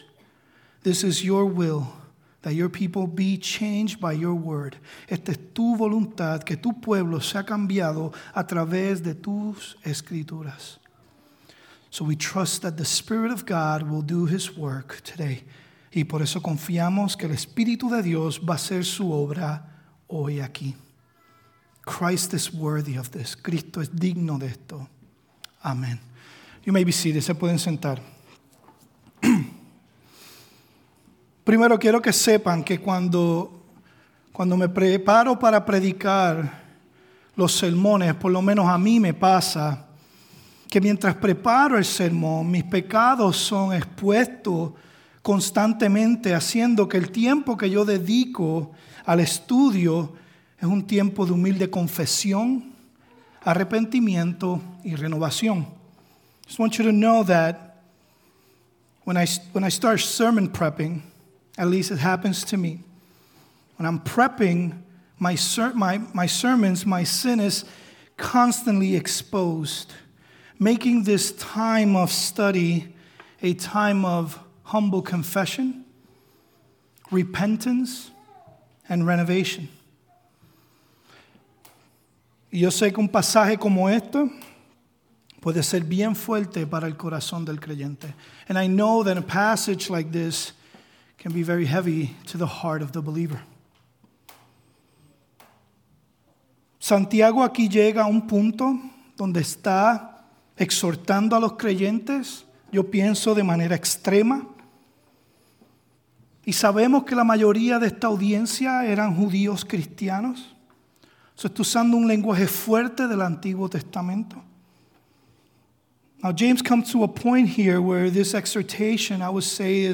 Sermons | Emmanuel Bible Church